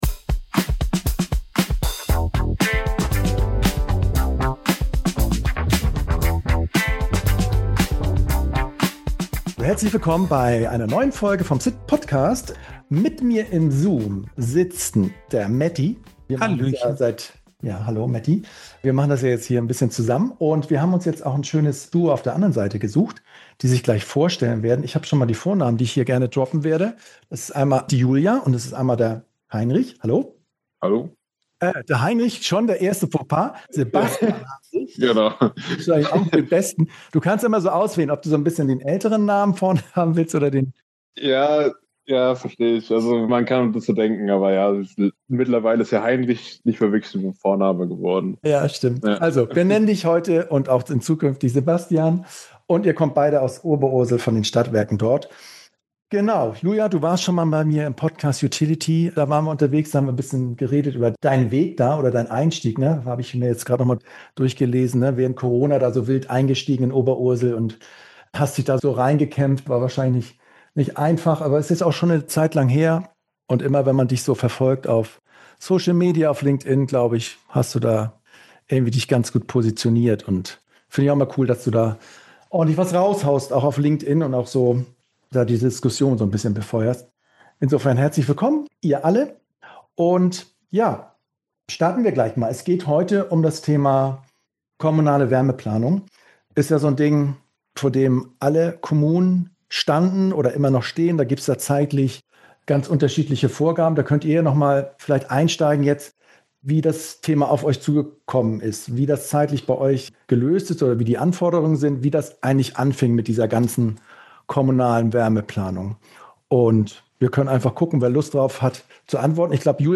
Ein ehrliches Gespräch über Chancen, Stolpersteine und warum Öffentlichkeitsarbeit kein Beiwerk ist, sondern Teil der Lösung.